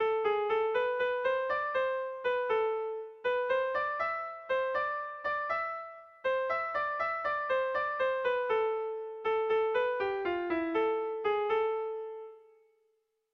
Air de bertsos - Voir fiche   Pour savoir plus sur cette section
Erlijiozkoa
ABDE